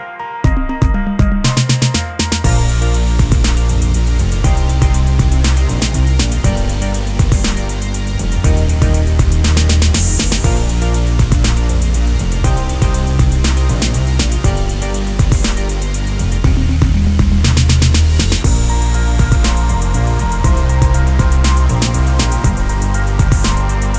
Without Female Lead R'n'B / Hip Hop 3:41 Buy £1.50